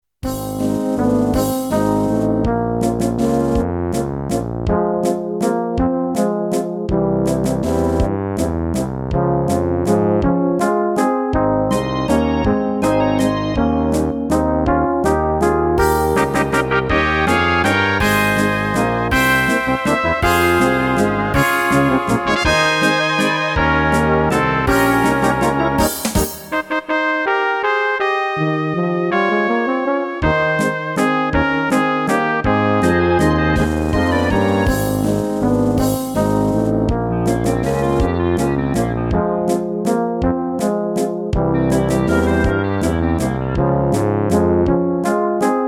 Rubrika: Národní, lidové, dechovka
- valčík